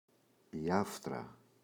άφτρα, η [‘aftra] – ΔΠΗ
άφτρα, η [‘aftra]: η άφθα, σπυράκια στη γλώσσα [μσν. άφθρα με ανομ. τρόπου άρθρ. [fθ > ft] < αρχ. ἄφθα ίσως παρετυμ. άφτρα].